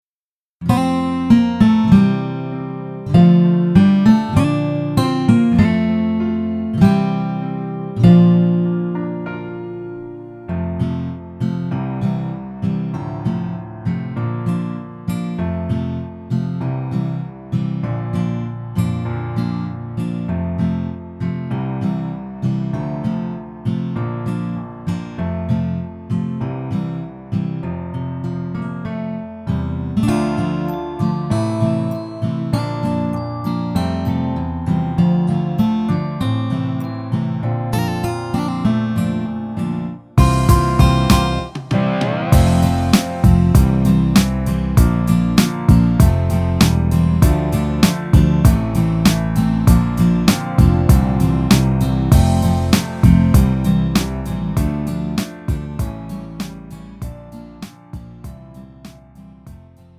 음정 남자키 3:07
장르 가요 구분 Pro MR
Pro MR은 공연, 축가, 전문 커버 등에 적합한 고음질 반주입니다.